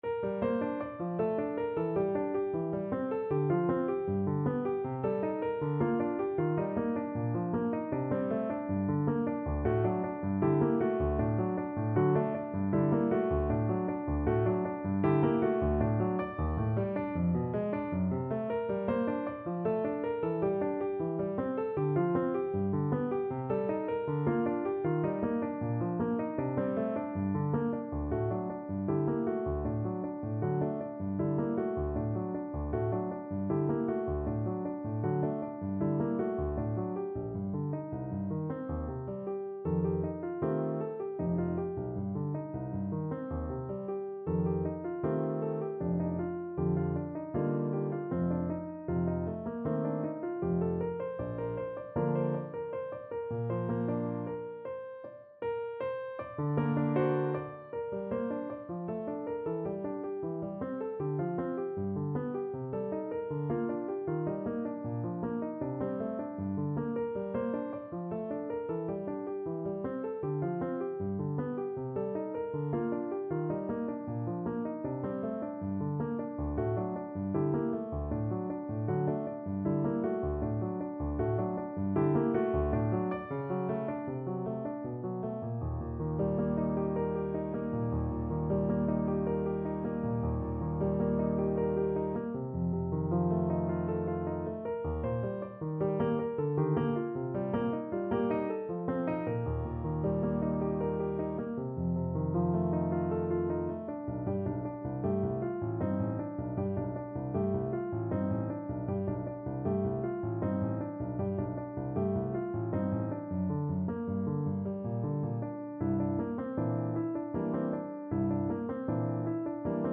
Trombone version
3/4 (View more 3/4 Music)
D4-D5
Classical (View more Classical Trombone Music)